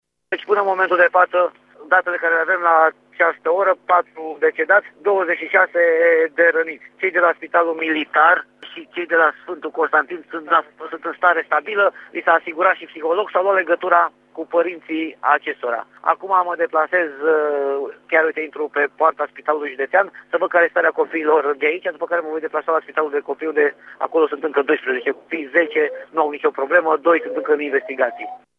Care este în acest moment starea răniților ne spune prefectul județului Brașov, Ciprian Băncilă: